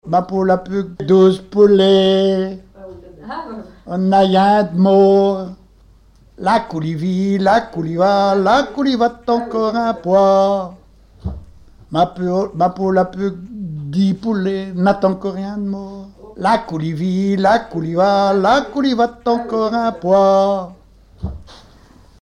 Mémoires et Patrimoines vivants - RaddO est une base de données d'archives iconographiques et sonores.
Genre énumérative
Témoignages et chansons
Pièce musicale inédite